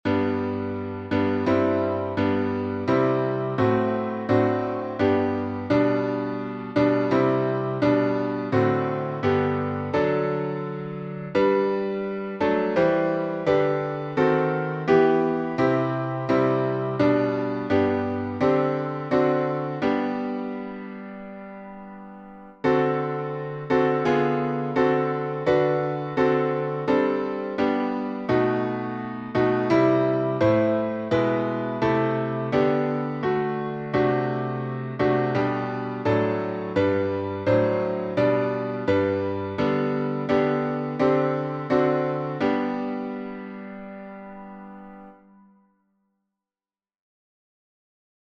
Key signature: G major (1 sharp) Time signature: 4/4